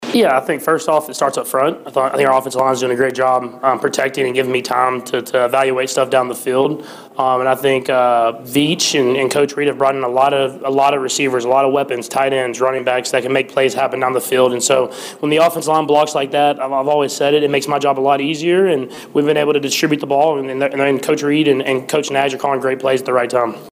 In the post-game press conference, Patrick Mahomes talked about the Chiefs’ offense